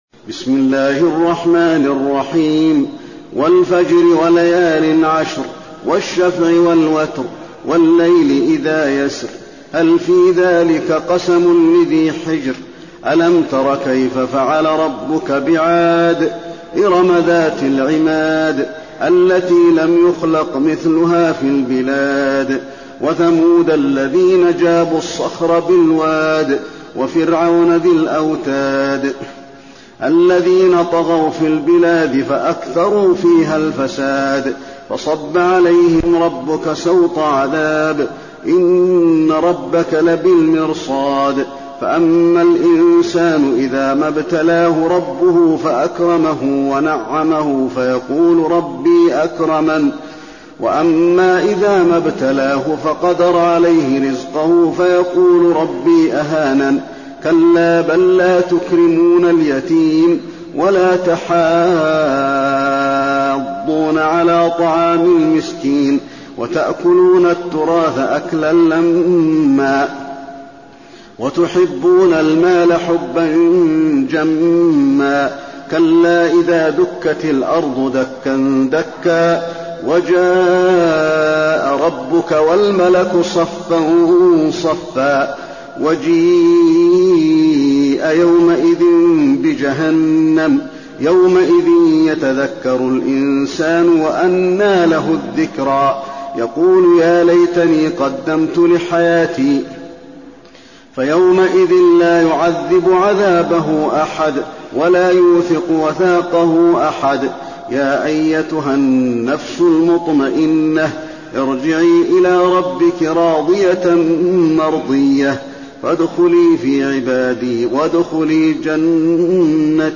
المكان: المسجد النبوي الفجر